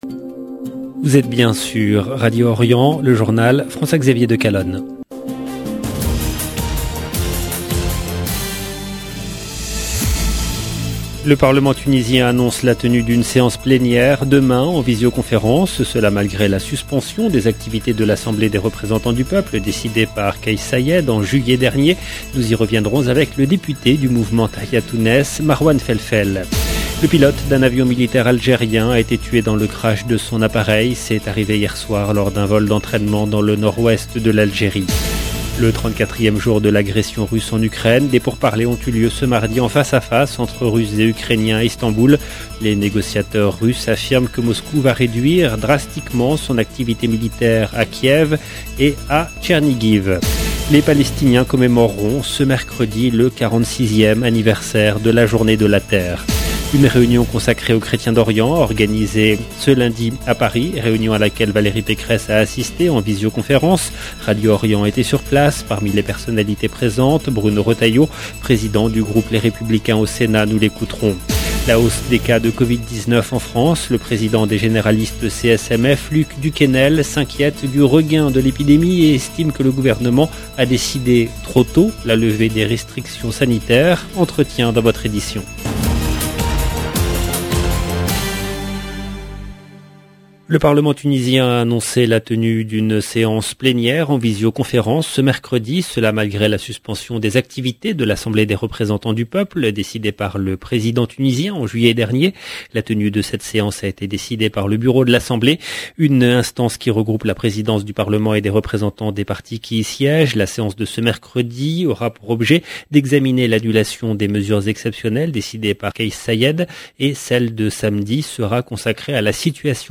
LE JOURNAL DU SOIR EN LANGUE FRANCAISE DU 29/03/22